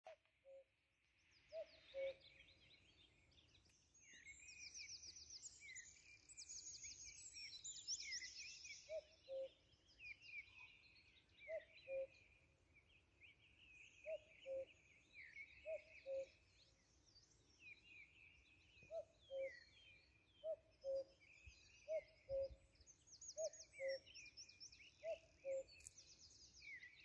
Going back thirty years when I was working as a gamekeeper putting pens up on the rearing field, Cuckoos would drive us crazy with their constant goo-ko’s all day long when they were looking for the lady Cuckoos.
Male Common Cuckoo calling
male-cuckoo.mp3